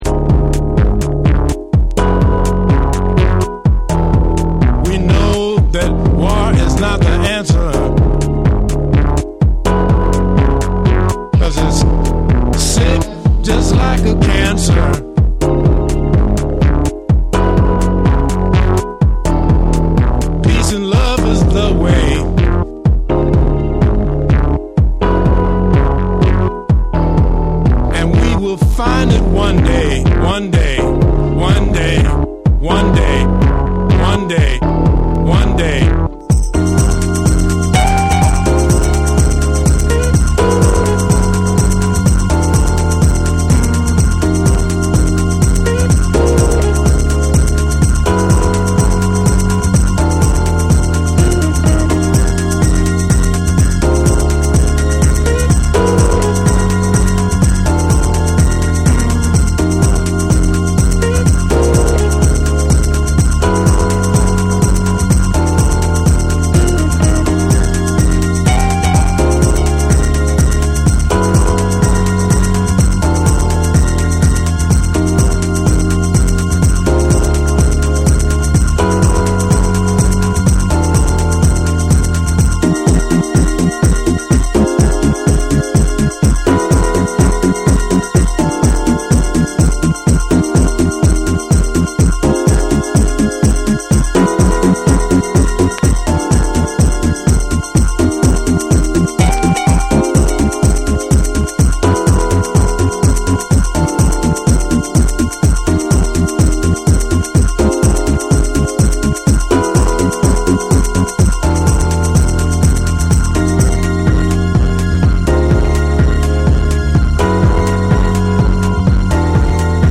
ソウルフルさとファンクネスを備えたダンス・ナンバーに仕上がっています。
SOUL & FUNK & JAZZ & etc / TECHNO & HOUSE / DETROIT